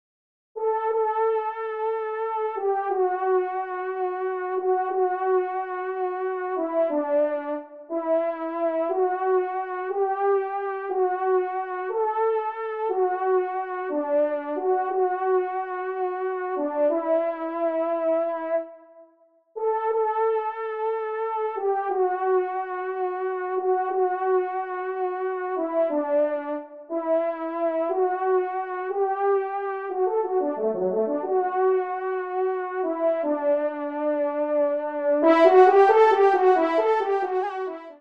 Genre : Musique Religieuse pour  Quatre Trompes ou Cors
Pupitre 1°Trompe